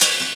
Track 02 - Percussion OS 04.wav